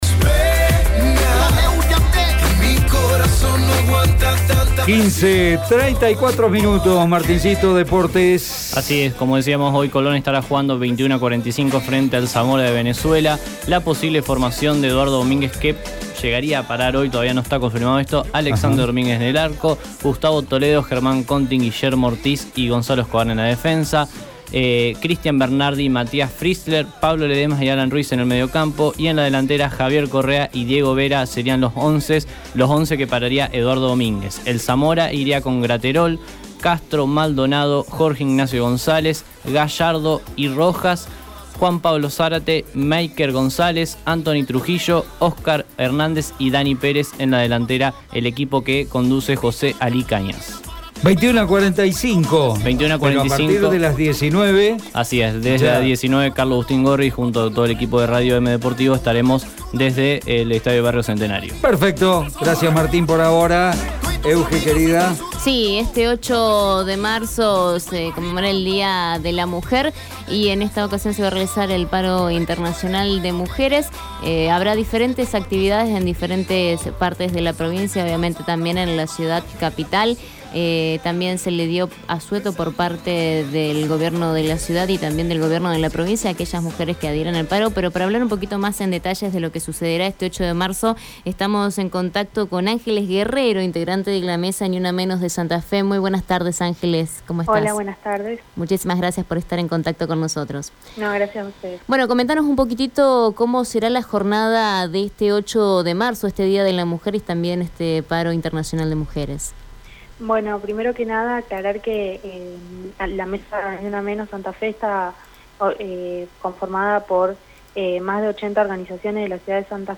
habló en el programa Abriendo Puertas de Radio EME sobre la marcha.